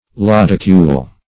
Search Result for " lodicule" : The Collaborative International Dictionary of English v.0.48: Lodicule \Lod"i*cule\, n. [L. lodicula. dim, of lodix, lodicis, a coverlet: cf. F. lodicule.]